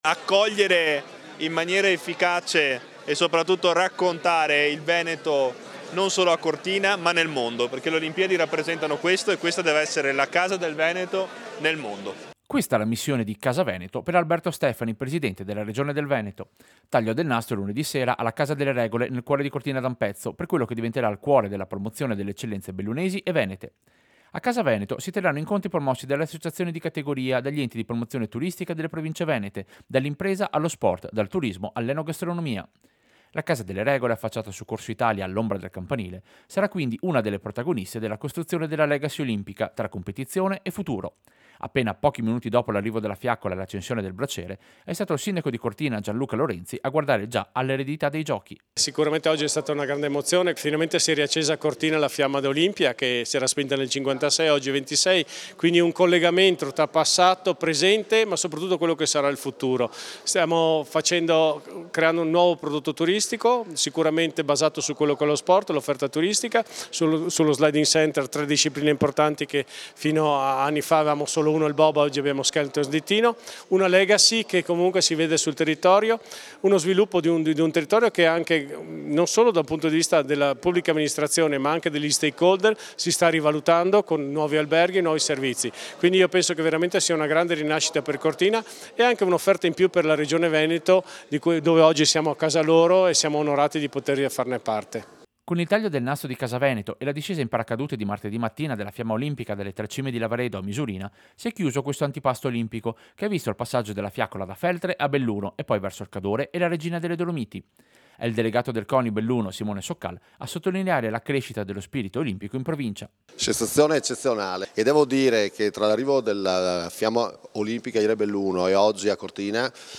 Servizio-Inaugurazione-Casa-Veneto-Cortina.mp3